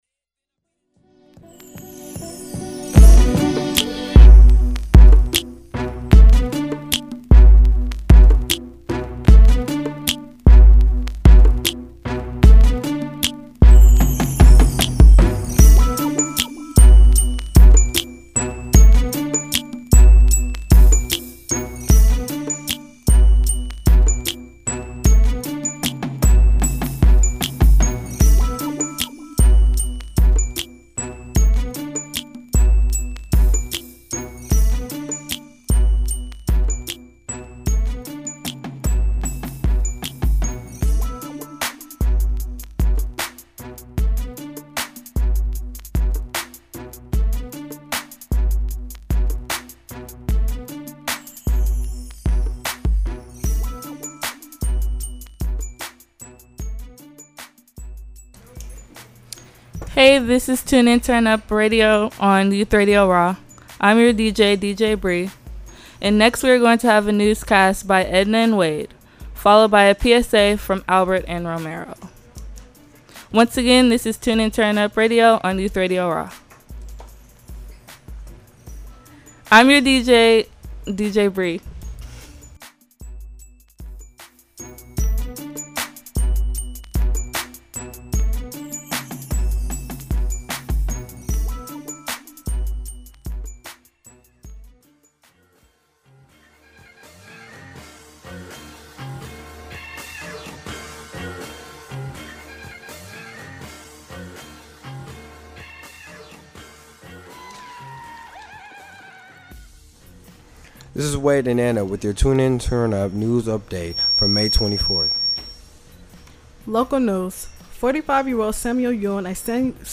Youth Radio Raw is a weekly radio show produced by Bay Area young people ages 14-18.